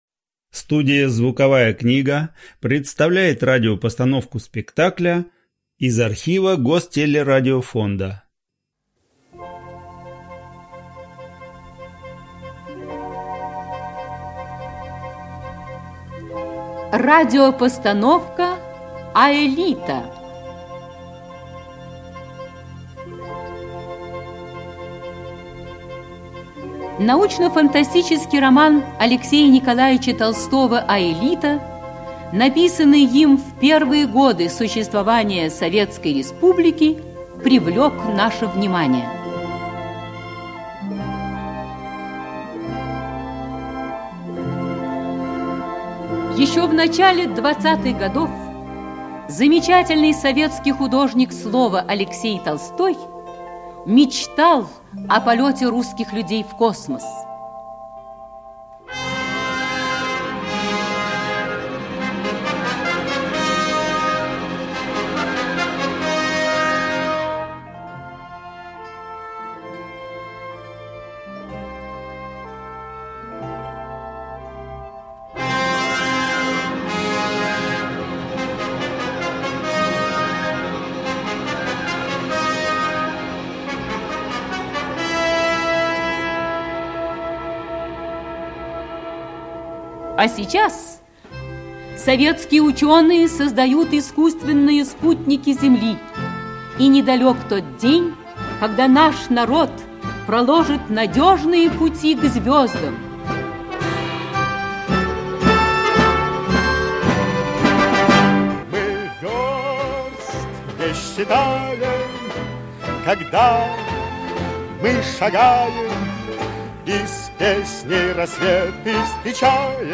Аэлита - слушать аудиокнигу онлайн